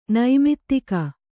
♪ naimittika